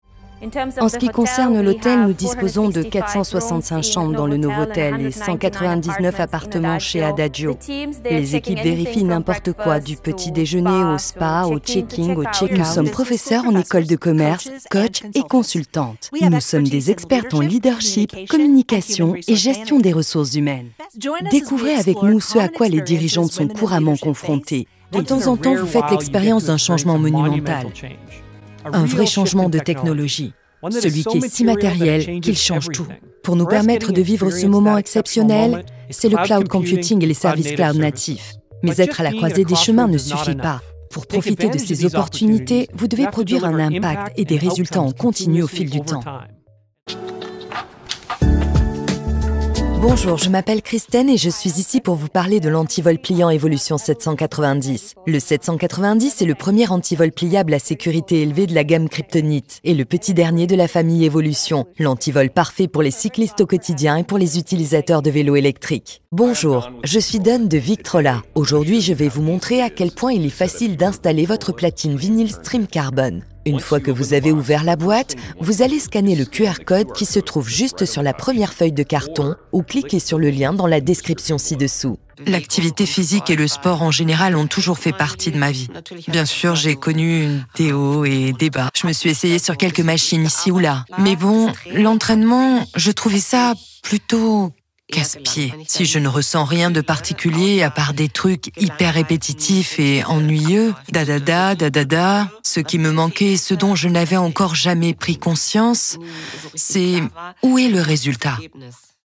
French Female Voice Over Artist
Female
Assured, Authoritative, Bright, Bubbly, Character, Children, Confident, Cool, Corporate, Deep, Engaging, Friendly, Natural, Posh, Reassuring, Soft, Versatile
French standard
Microphone: NEUMANN TLM49
Audio equipment: Apollo Twin MKII universal audio - Cleransonic recording booth